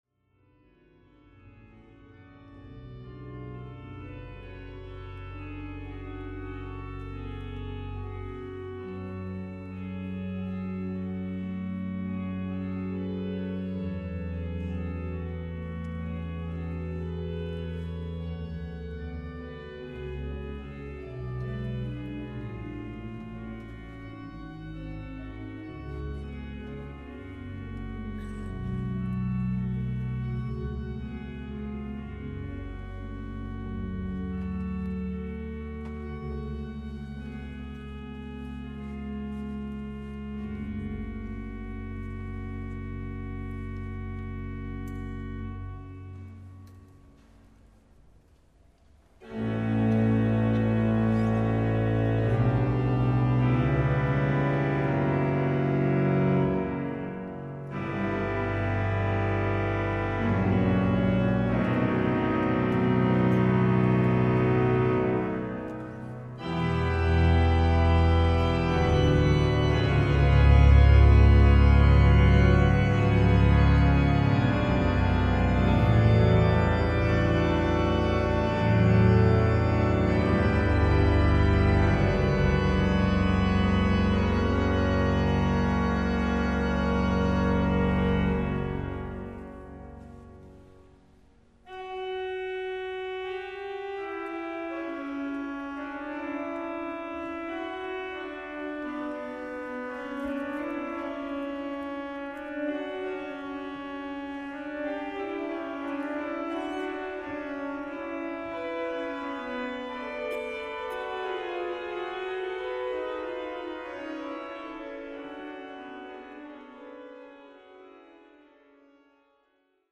Handglocken
Die Musik der Handglocken ist durch ihren hoch resonanten und einzigartig anziehenden Klang charakterisiert, der durch die Obertöne zustande kommt.
Erfreuen Sie sich an der wunderbaren Musik und dem zarten Glockenklang des 8-Hände (4-Spieler/innen) bestehenden Handglockenensembles "Mai", das mit großer Handfertigkeit 37 Glocken zum Erklingen bringt.